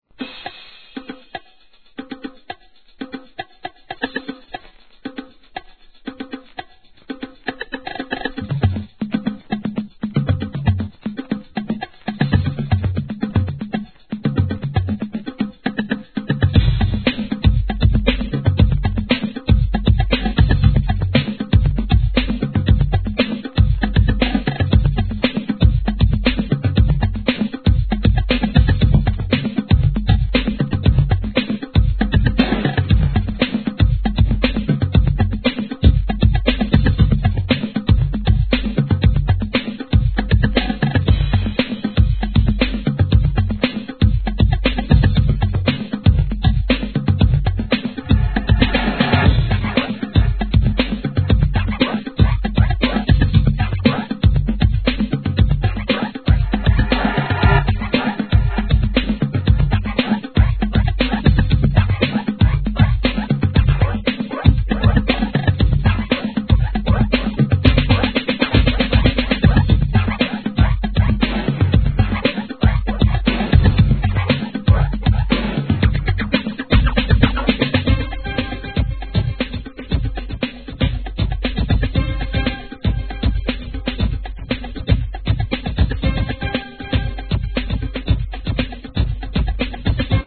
HIP HOP/R&B
ヴォーカル・ダンスナンバー! スクラッチをはめ込んだDUB ver.もNICE!!